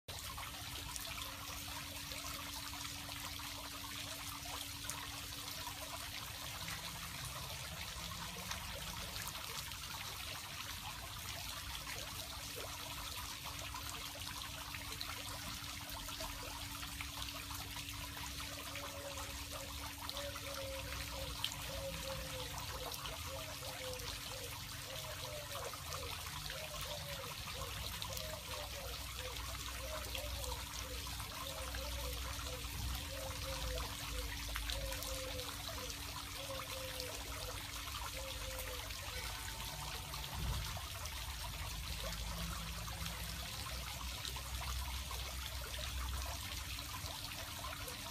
دانلود صدای چشمه 1 از ساعد نیوز با لینک مستقیم و کیفیت بالا
جلوه های صوتی
برچسب: دانلود آهنگ های افکت صوتی طبیعت و محیط دانلود آلبوم صدای چشمه و فواره از افکت صوتی طبیعت و محیط